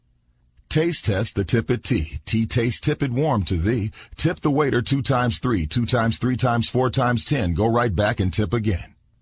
tongue_twister_05_01.mp3